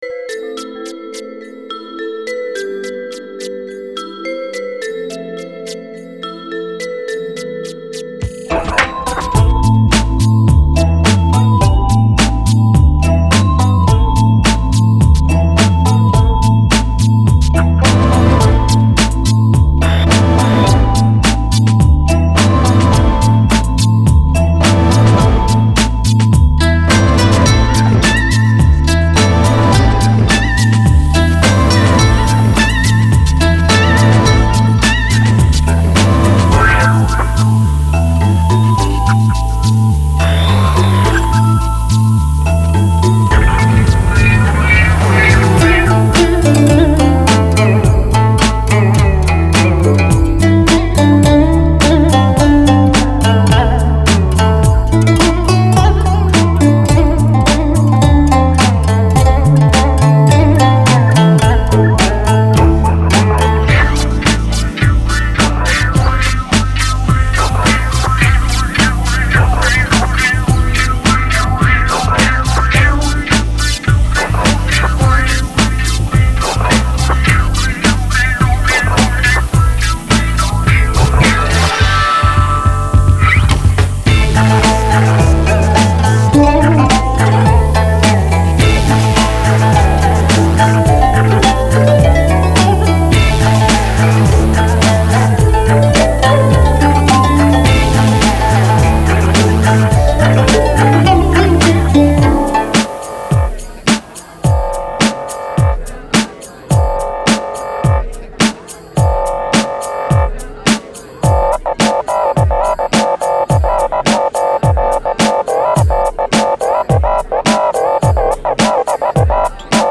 而对于各种声效（如水声、鸣叫声等）的处理，也全然不是古典古筝所能够胜任的，整首曲子充满了“杀气”。